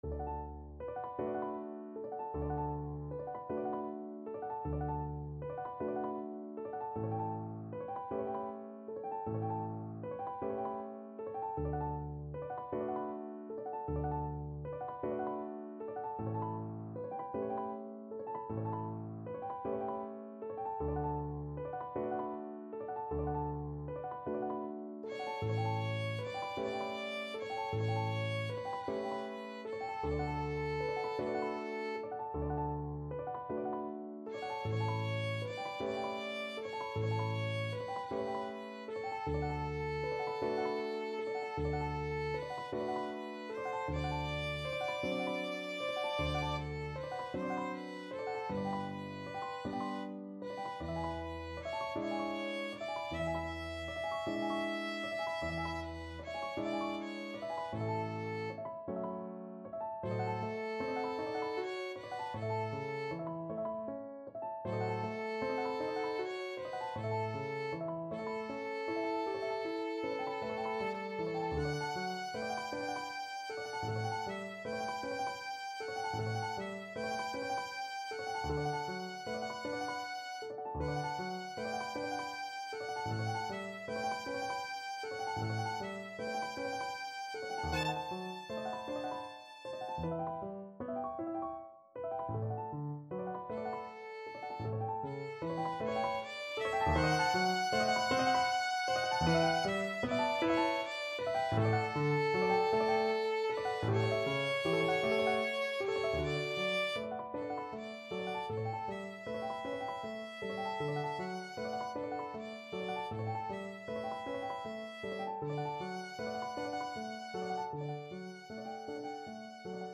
Violin 1Violin 2Piano
6/8 (View more 6/8 Music)
Moderato . = c. 52
Classical (View more Classical Violin Duet Music)